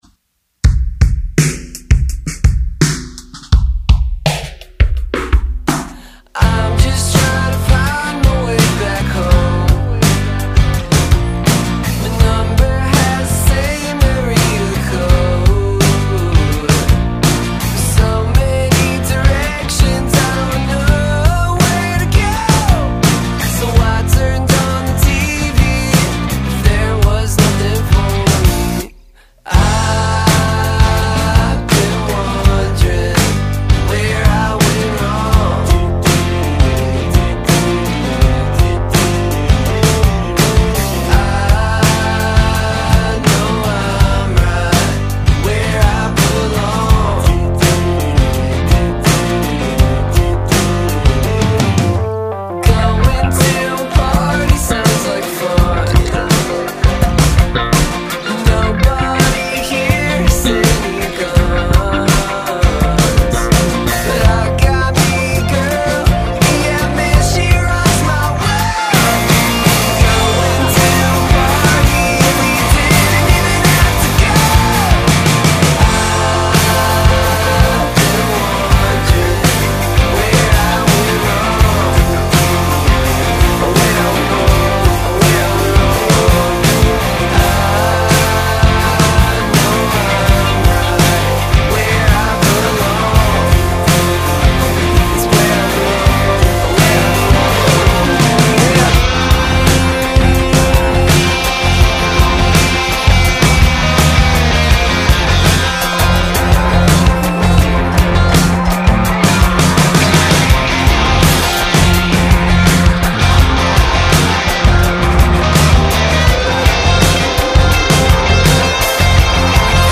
high-energy blast of punk-meets-noise-pop
raw DIY spirit